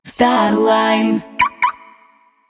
Примеры звуковых логотипов